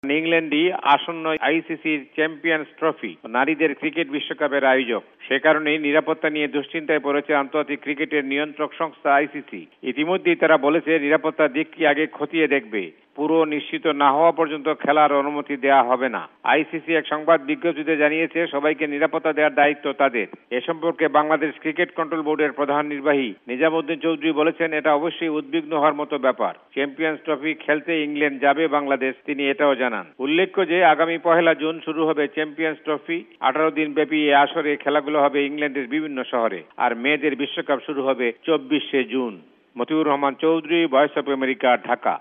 রিপোর্ট (ক্রিকেট)